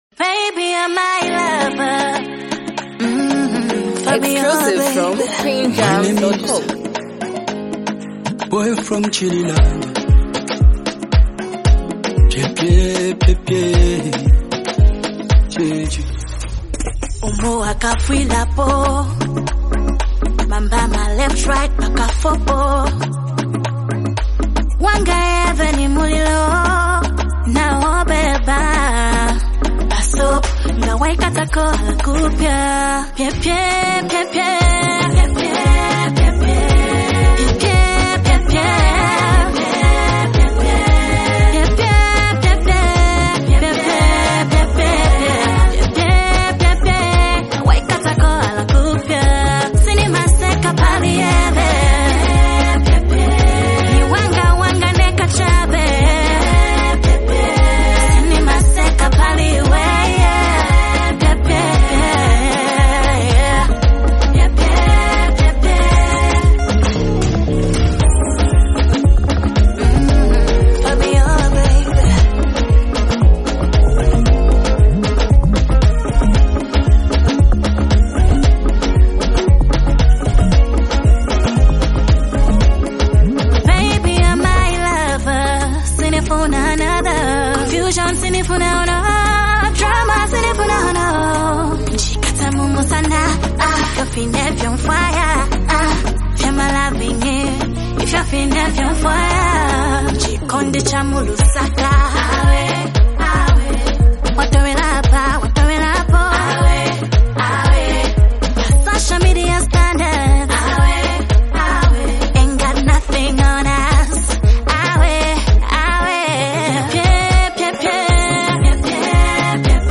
heartfelt love song